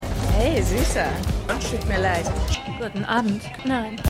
Pssica_1x03_ProstituierteHellviolett_Rot.mp3